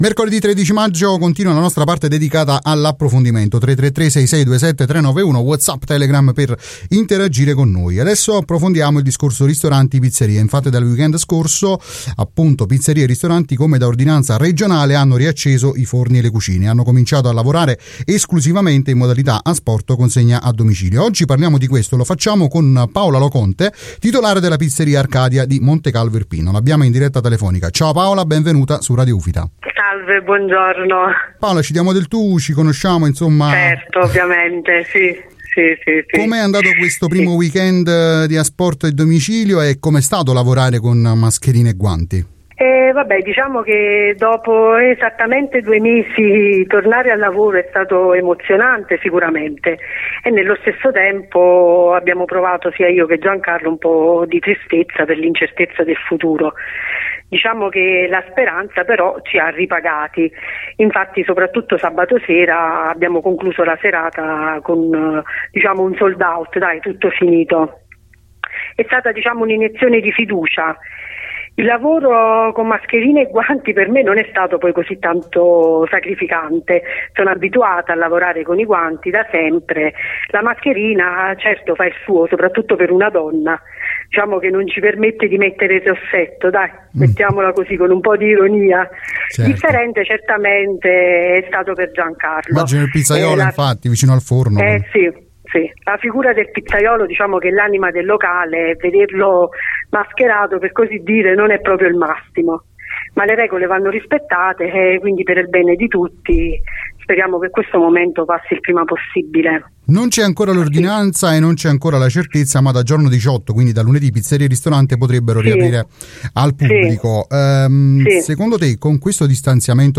E’ intervenuta questa mattina nel corso del Morning Show di Radio Ufita “Buongiorno col Sorriso”